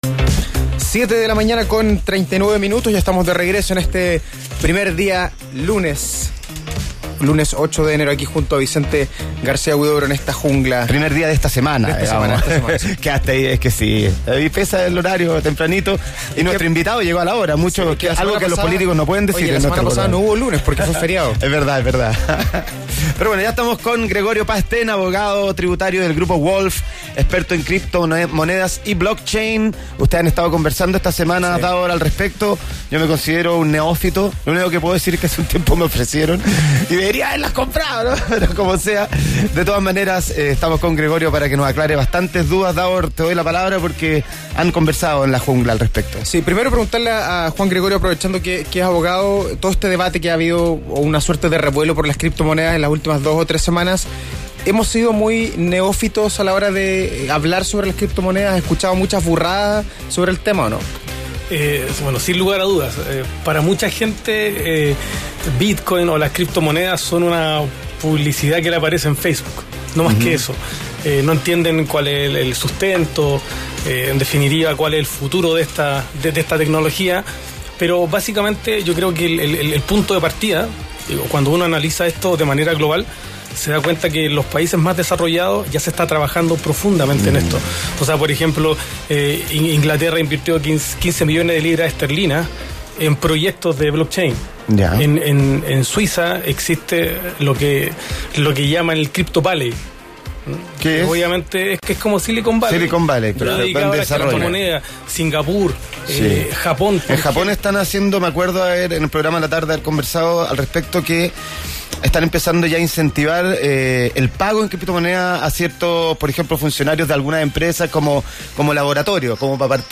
¿Deben ser declarados los movimientos hechos de compra y venta de criptomonedas? ¿El SII ya ha tomado decisiones al respecto de la tributación de ellas? Todo esto, en la siguiente entrevista.